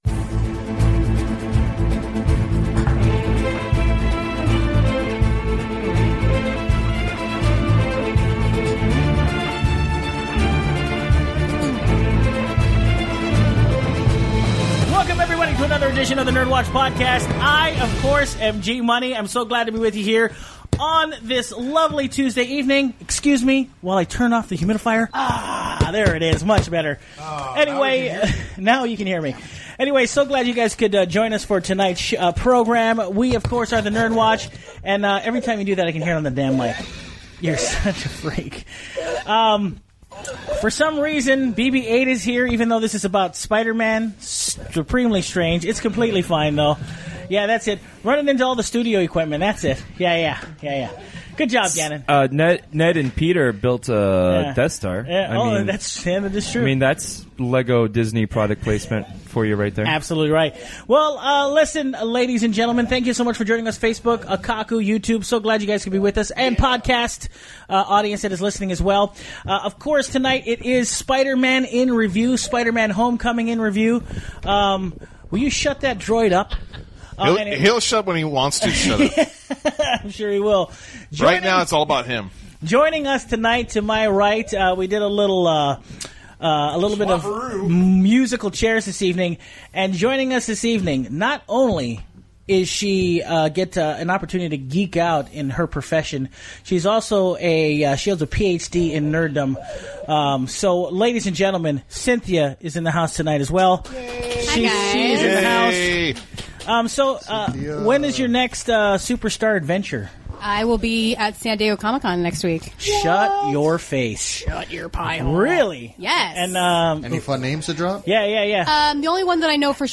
All recorded live at Maui Comics & Collectibles.